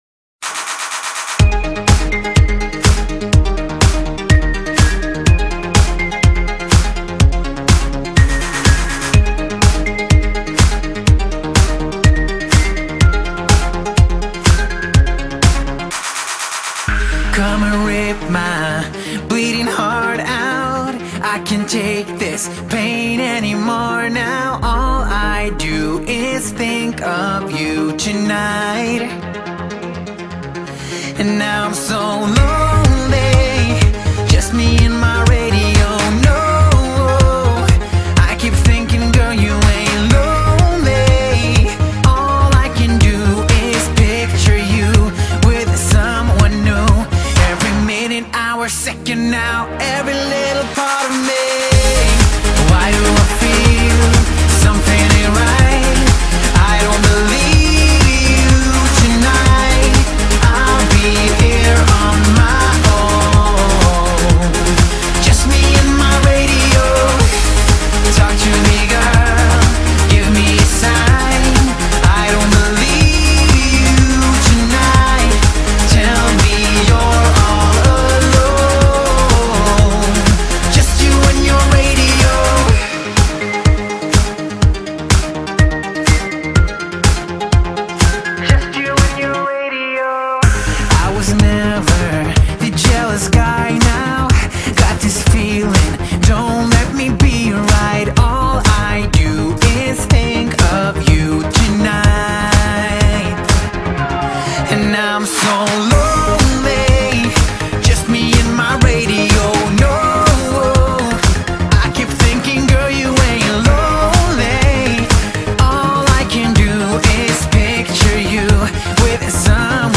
Популярная музыка